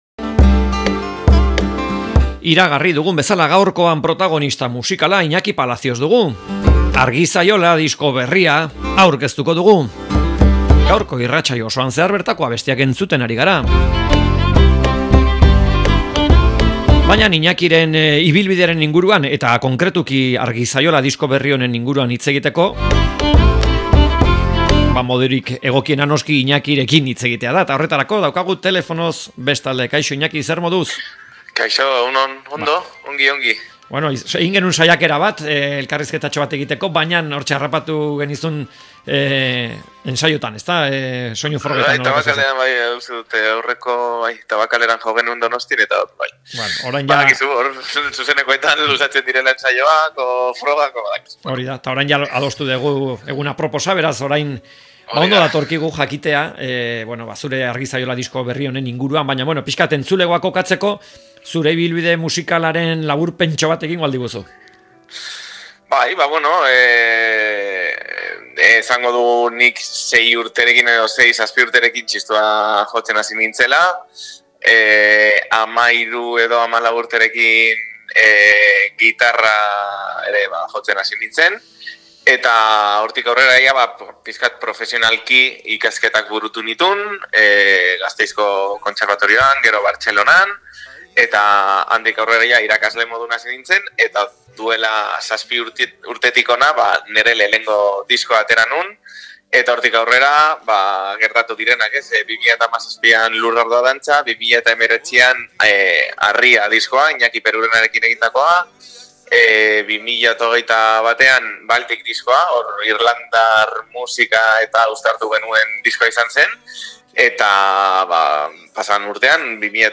Elkarrizketak